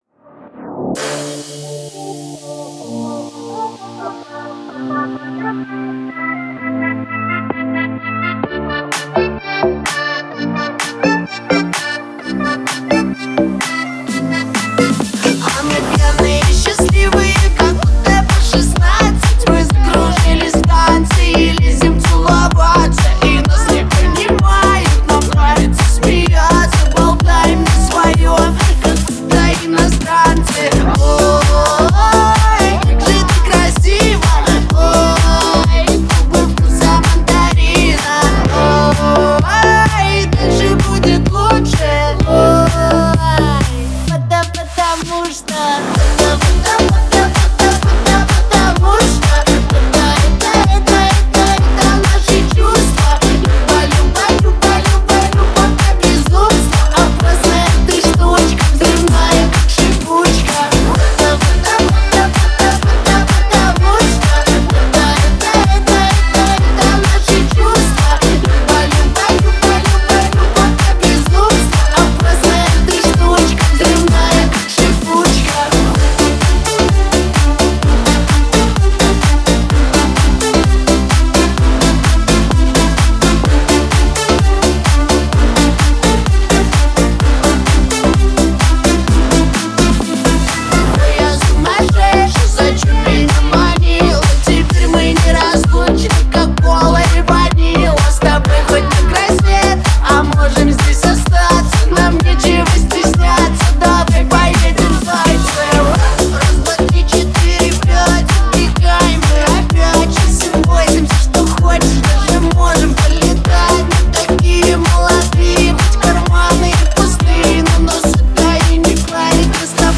это яркий трек в жанре EDM